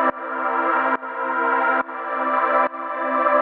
GnS_Pad-dbx1:2_140-C.wav